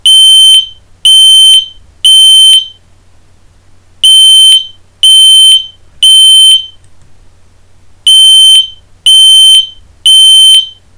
• Lautstärke im Test: 99,0 dBA
fireangel-st-620-rauchmelder-alarm.mp3